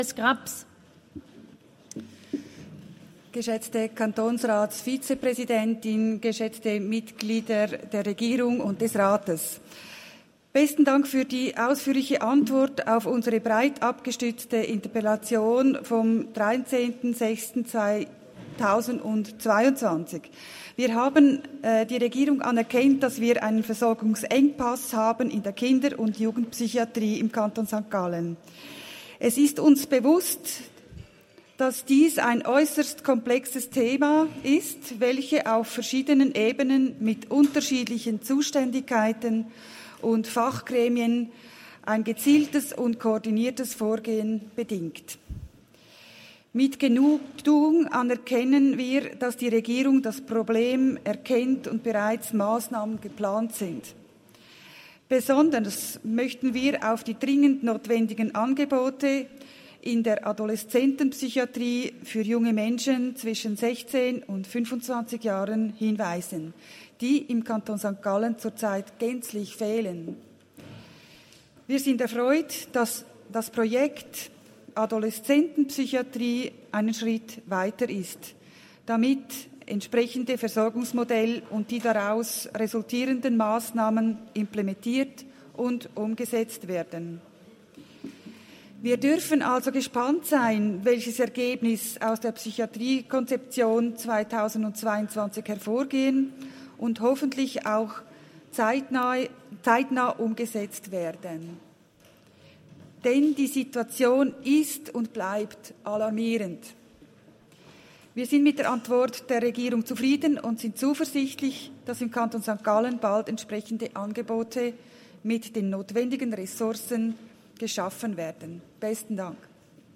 20.9.2022Wortmeldung
Session des Kantonsrates vom 19. bis 21. September 2022